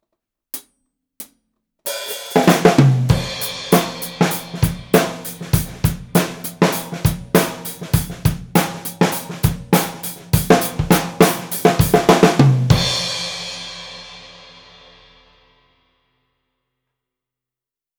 【サンプル音源あり】MXL V67G 一本のみでドラムレコーディング！
すべて、EQはしていません。
続きまして、ドラムの目の前にマイキングしました。
高さは、タムの位置ぐらいですね！